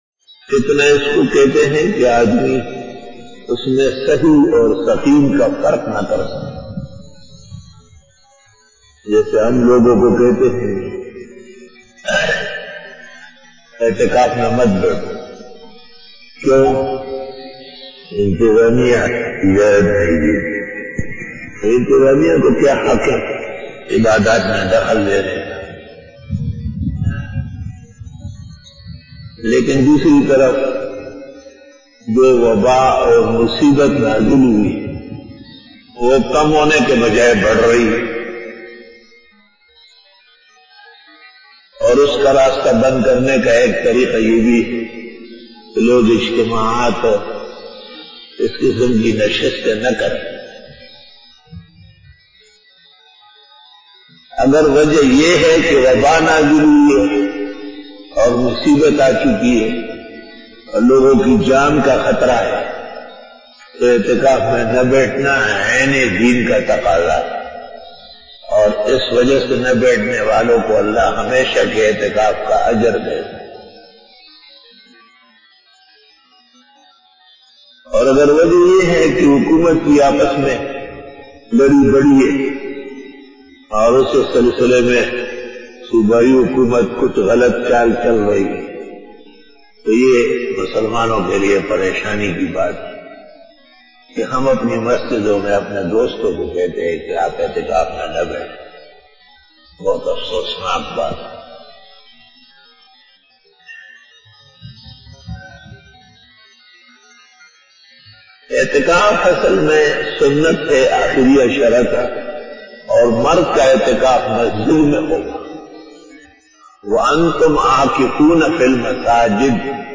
After Fajar Byan